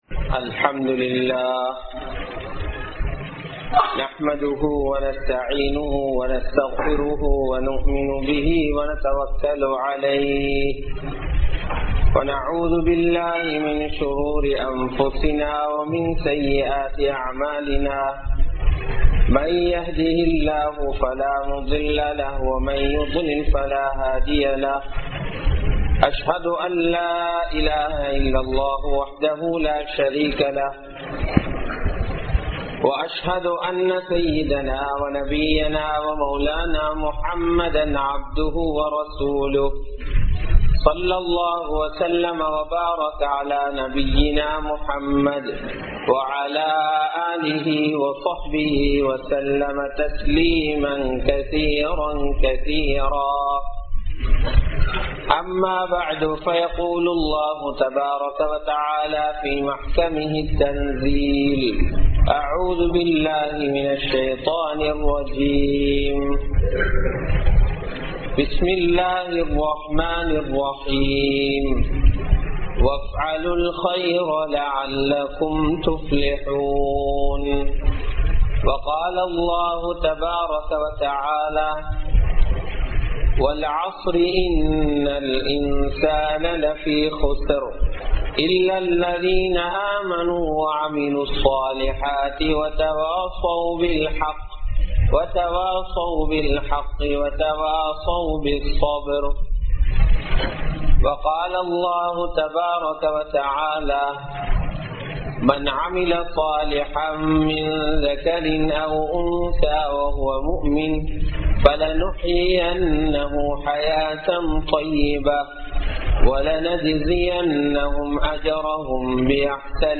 Masjidhin Niruvaham Entraal Enna? (மஸ்ஜிதின் நிருவாகம் என்றால் என்ன?) | Audio Bayans | All Ceylon Muslim Youth Community | Addalaichenai